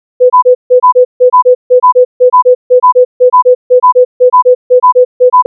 • sequence of sounds with a repeating pattern LHL-LHL-... (where L=low tone and H=high tone)
• at larger frequency separations, the high and low sounds form ________ streams and the rhythm is lost [
sound-morse.wav